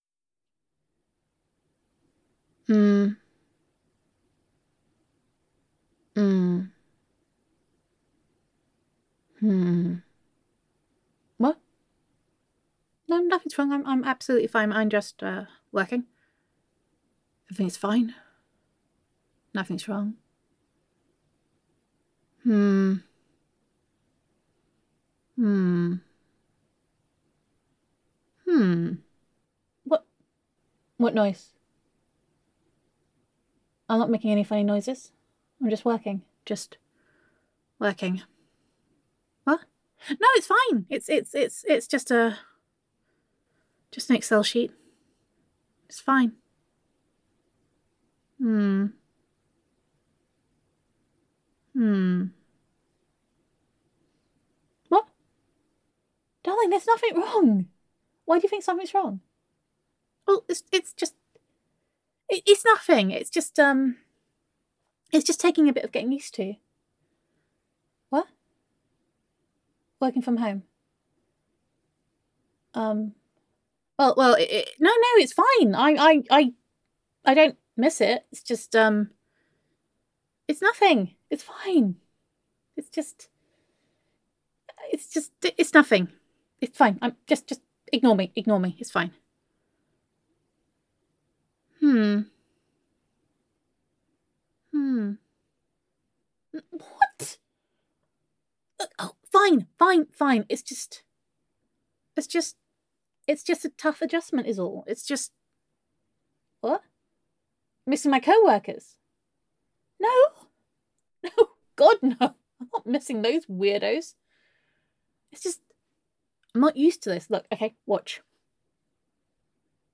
[F4A] Day Two - Being Stationery [Girlfriend Roleplay][Self Quarantine][Domestic Bliss][Gender Neutral][Self-Quarantine With Honey]